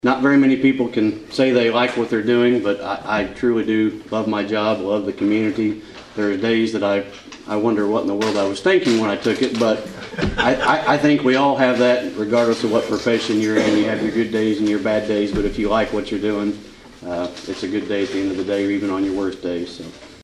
Carter Gives Update on new Carmi PD Building at Kiwanis Meeting
Carter gave an update on the new Carmi Police Department, the old Leggett’s Market building, at the Thursday Kiwanis meeting.